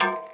9 Harsh Realm Guitar Noise Mid.wav